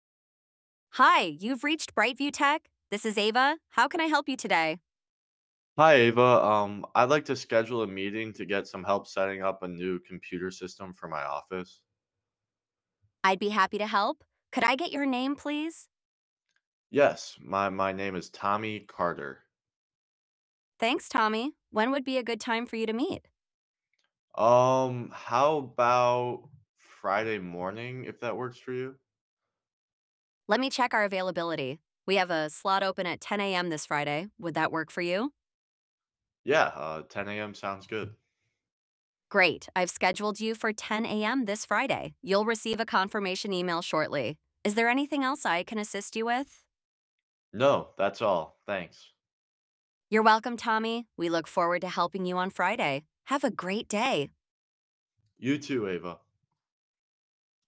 From only $149.99 Monthly 14 day free trial Simplify scheduling & meeting management Designed to effortlessly automate with a smart AI scheduling system Listen to demo conversation now TRY NOW!